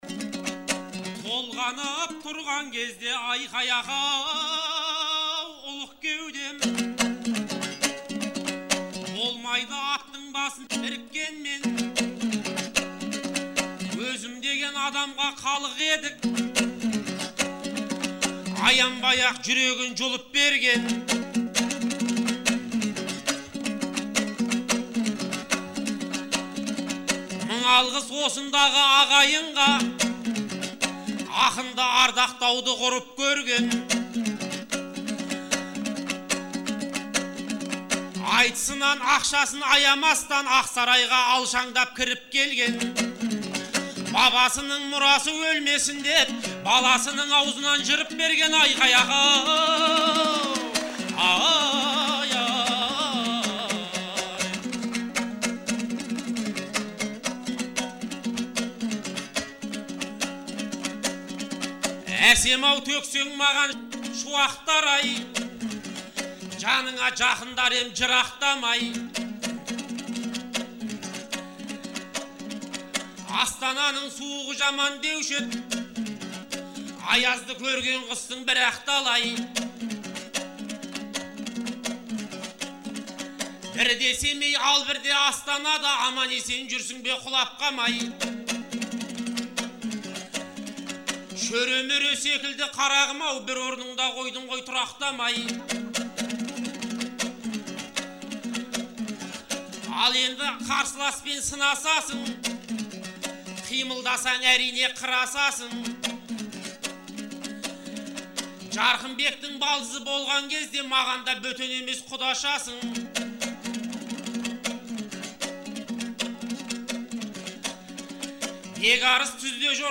Наурыздың 15-сі мен 16-сы күні Шымкент қаласында «Наурыз айтысы» өтті.